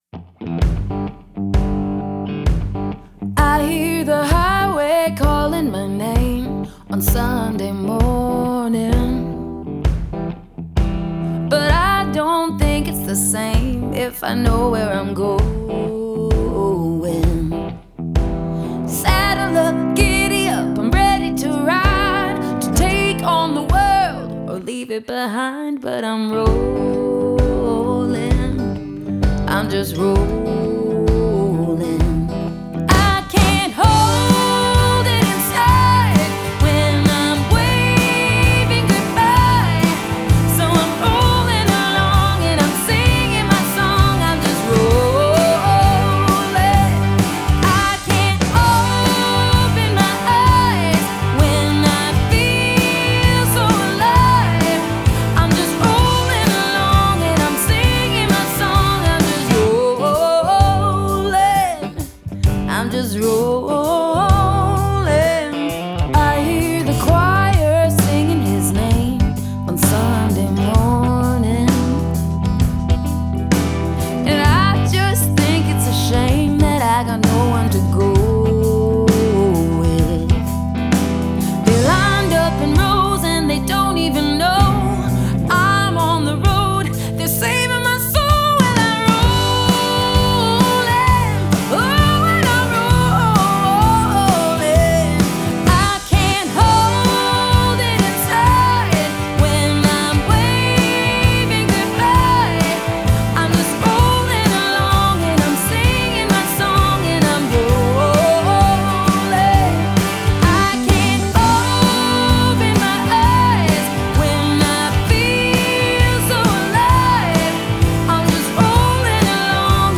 28 min of Western Canadian indie music mix